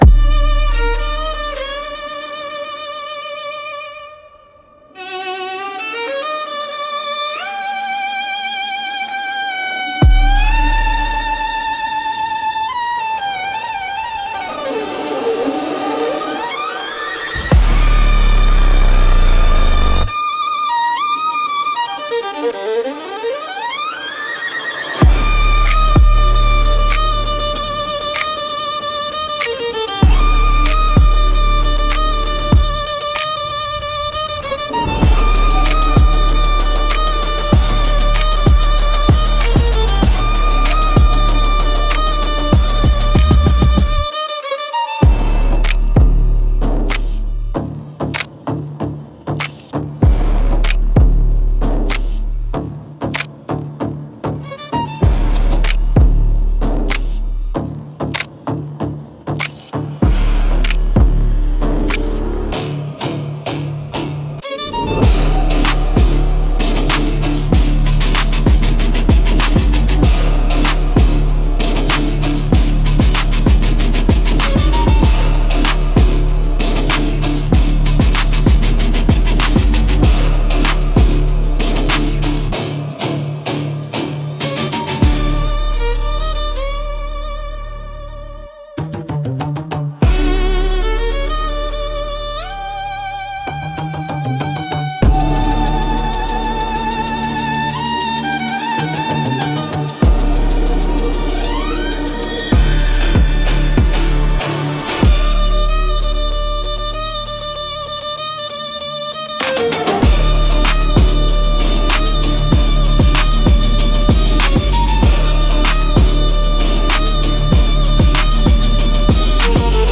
Instrumental - Your Move, Darling 2.47